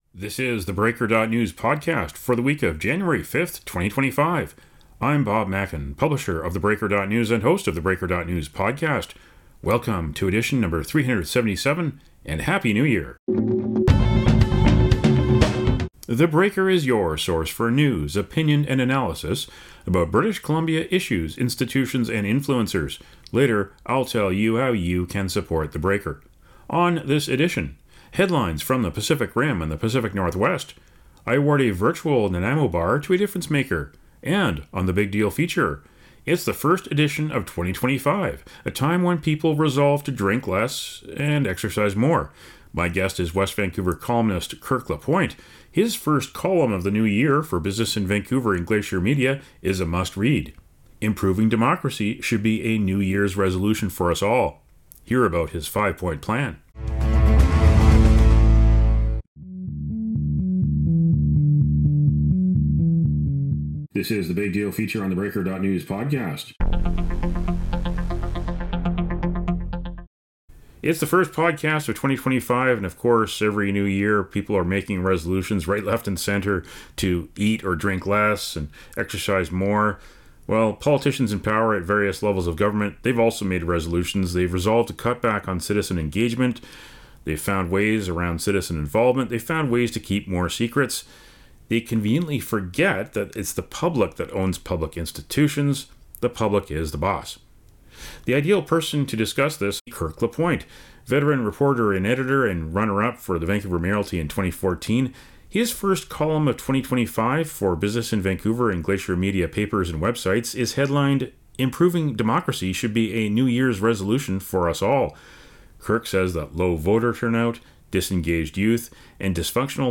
Listen to the interview for a five-point plan to empower the people. Plus Pacific Rim and Pacific Northwest headlines.